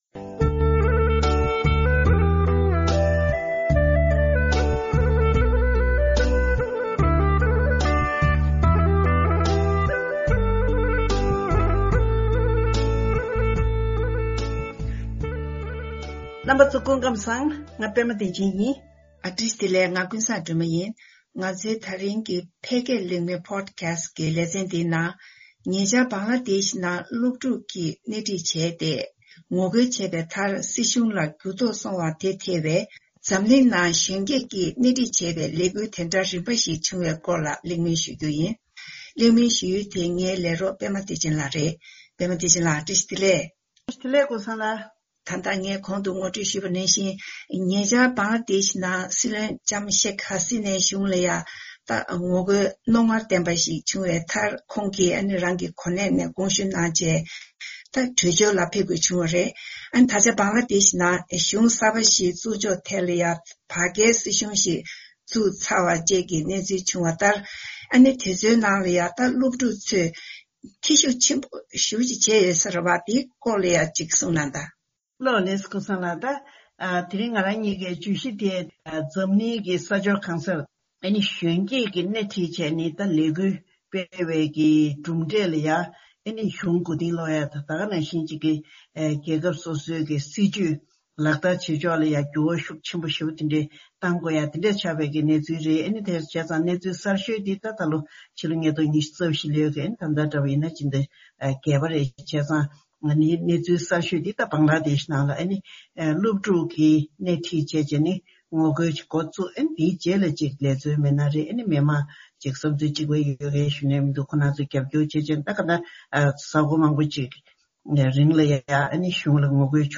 ༄༅། ཐེངས་འདིའི་ཕལ་སྐད་གླེང་མོལ་པོ་ཌ་ཁ་སེ་ལེ་ཚན་ནང་ ཉེ་ཆར་བྷང་ལ་དེཤེ་ནང་སློབ་ཕྲུག་གིས་སྣེ་ཁྲིད་བྱས་ཏེ་ངོ་རྒོལ་སྤེལ་བའི་མཐར་སྲིད་གཞུང་ལ་འགྱུར་ལྡོག་སོང་བ་དེ་ཐེ་བའི་ འཛམ་གླིང་ནང་གཞོན་སྐྱེས་ཀྱིས་སྣེ་ཁྲིད་བྱས་པའི་ལས་འགུལ་དེ་འདྲ་རིམ་པ་ཞིག་བྱུང་པའི་སྐོར་གླེང་མོལ་ཞུས་པ་ཞིག་གསན་རོགས་གནང།